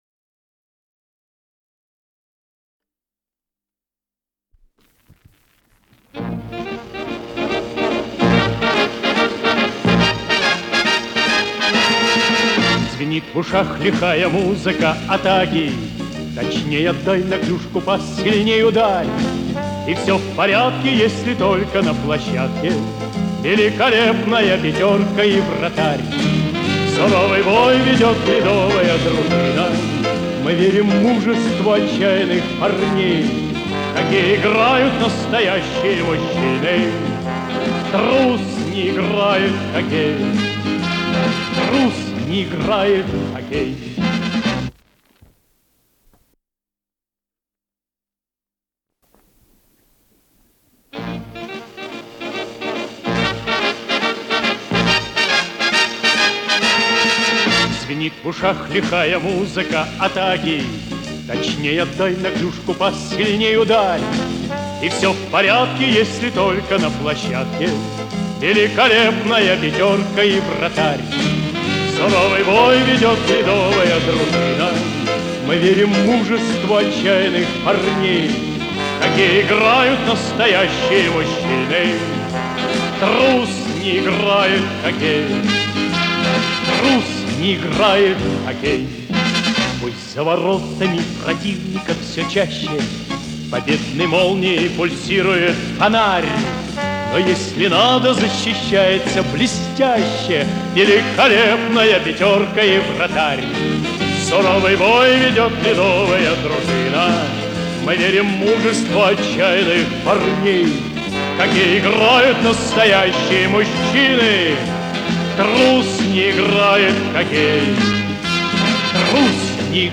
с профессиональной магнитной ленты
Скорость ленты38 см/с
ВариантМоно
МагнитофонМЭЗ-109М